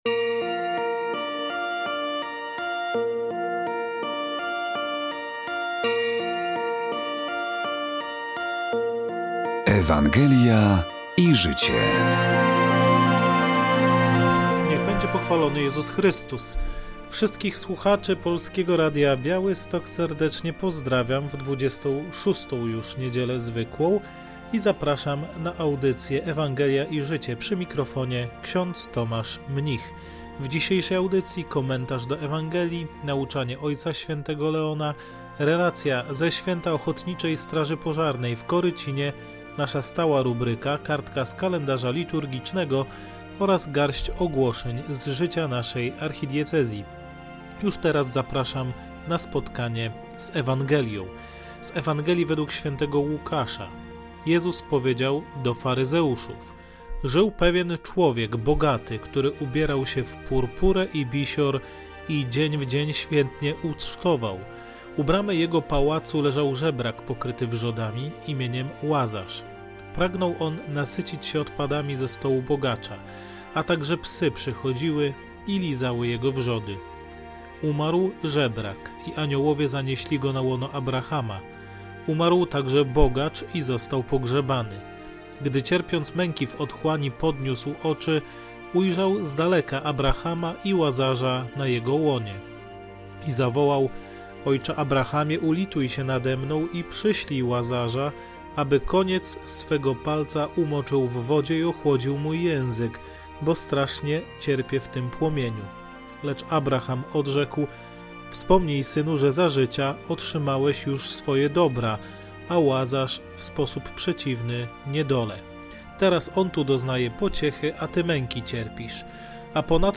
W audycji rozważanie do niedzielnej Ewangelii, nauczanie Ojca Świętego, relacja ze święta Ochotniczej Straży Pożarnej w Korycinie, stała rubryka: kartka z kalendarza liturgicznego oraz garść ogłoszeń z życia naszej Archidiecezji.